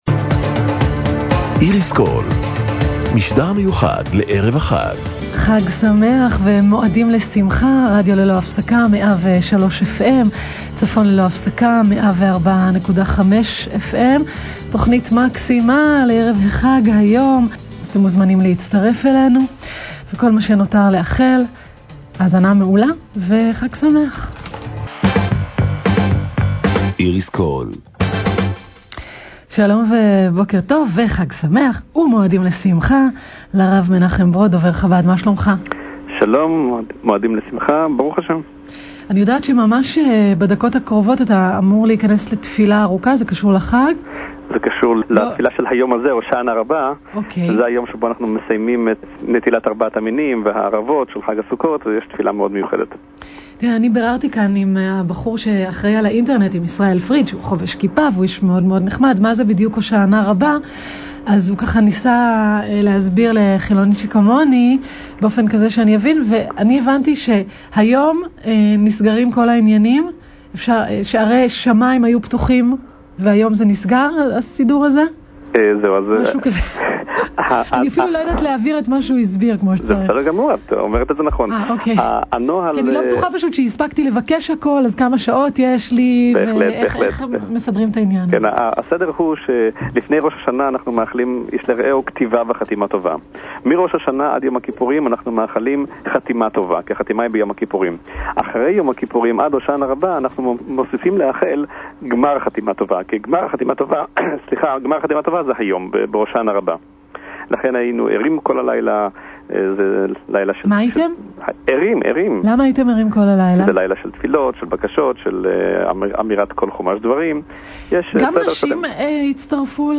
בפינה התעניינה המראיינת על מהותם של הושענא-רבה ועל שמחת-תורה